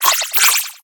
Cri de Rongourmand dans Pokémon HOME.